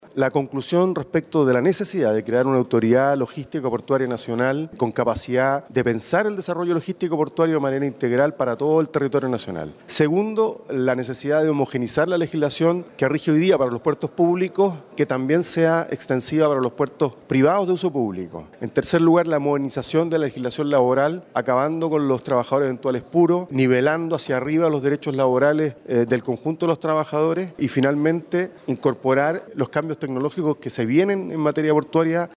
En el debate hubo discrepancias en torno a establecer una tasa portuaria a beneficio de los municipios de las ciudades puertos, como explicó el Diputado socialista Marcelo Díaz, quien se refirió a las conclusiones del grupo legislativo.